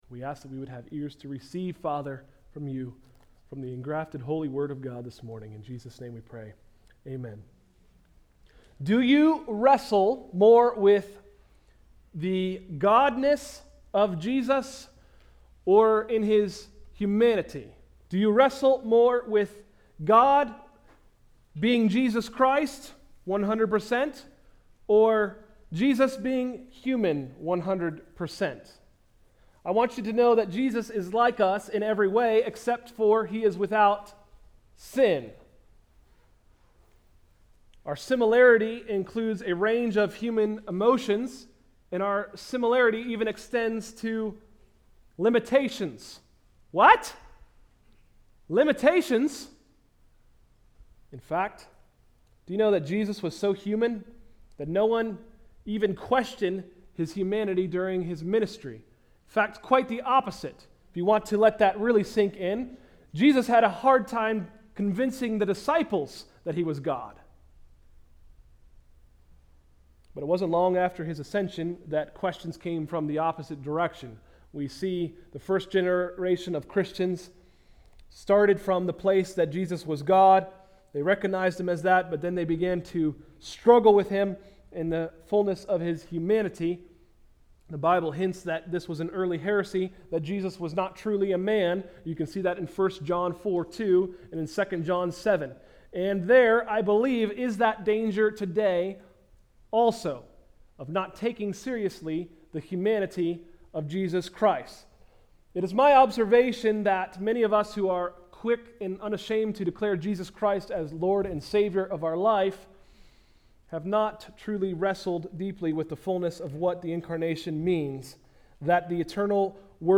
Sermons: “God’s Mission pt.2” – Tried Stone Christian Center